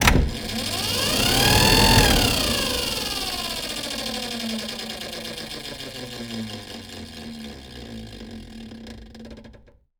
TransportHangar.wav